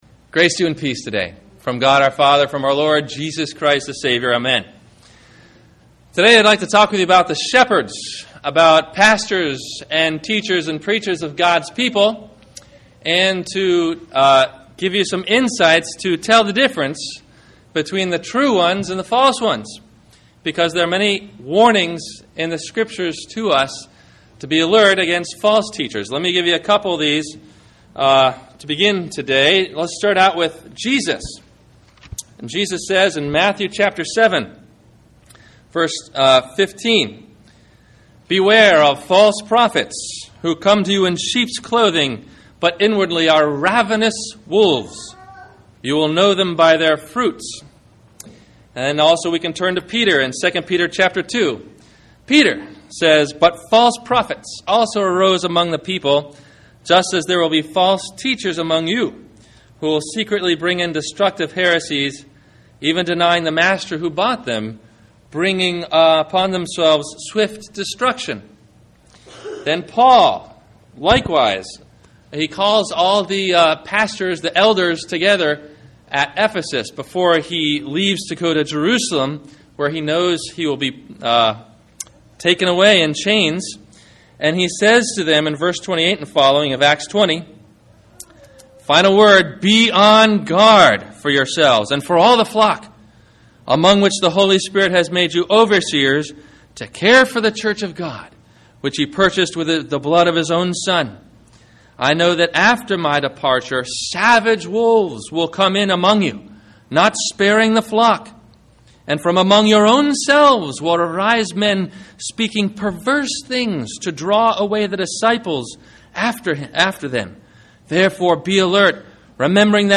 Secrets Taught in Liberal Seminaries - Sermon - July 12 2009 - Christ Lutheran Cape Canaveral